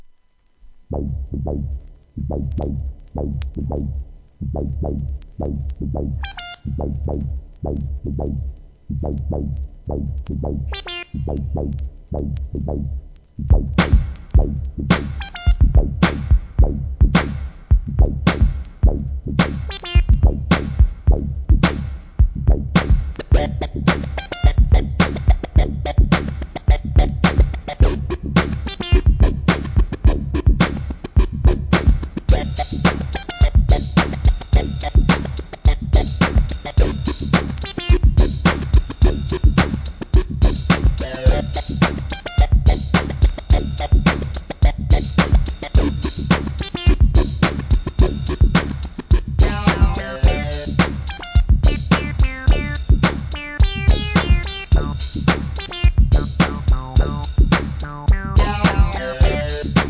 エレクトロ〜ブレイクビーツ